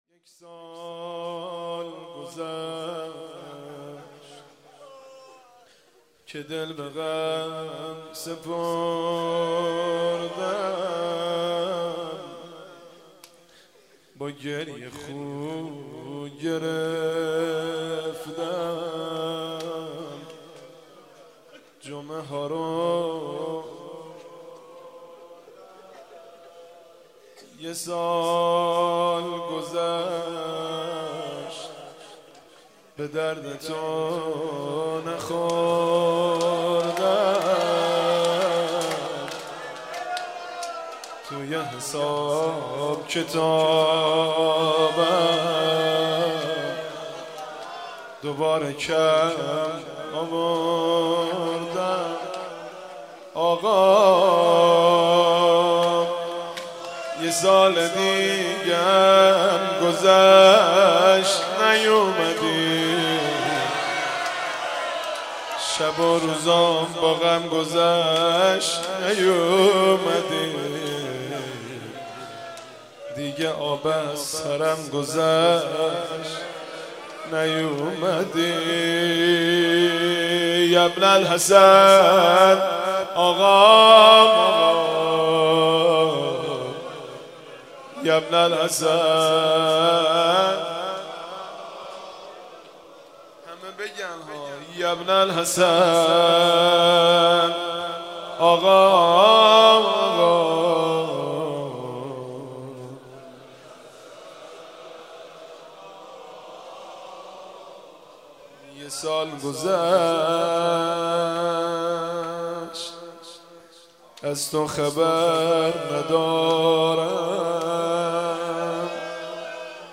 حاج مهدی رسولی؛ مناجات با امام زمان (عجّل‌الله)